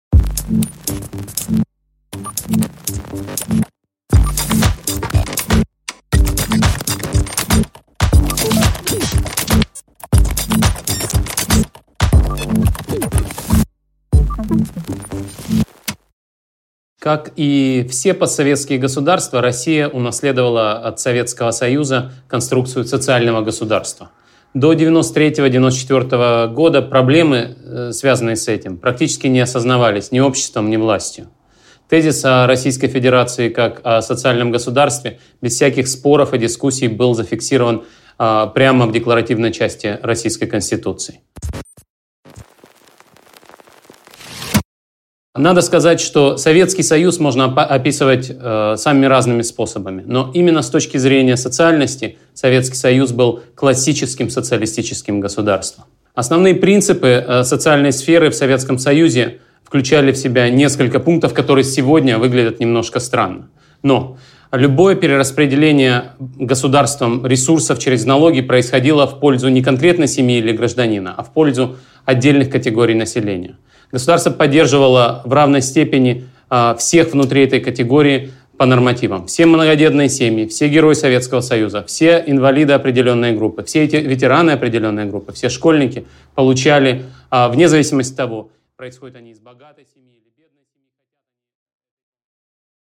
Аудиокнига Социальное государство: эволюция социальной сферы | Библиотека аудиокниг